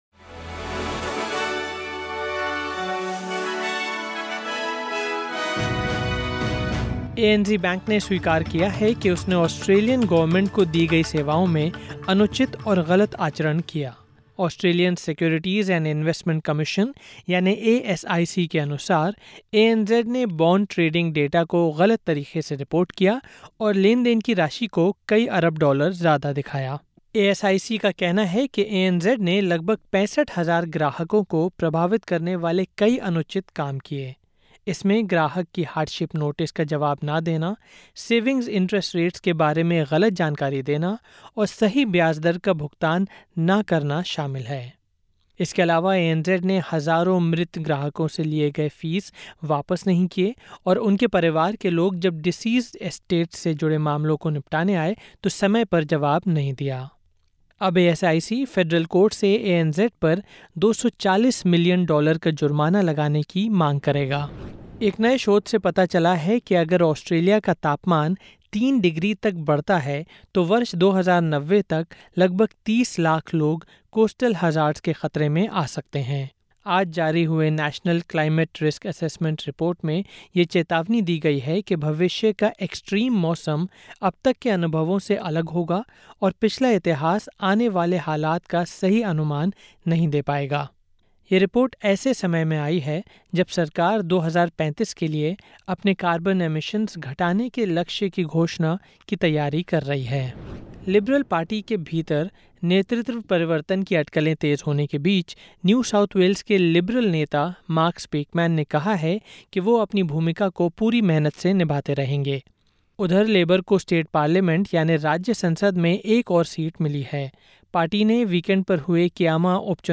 ऑस्ट्रेलिया और भारत से 15/09/2025 के प्रमुख समाचार हिंदी में सुनें।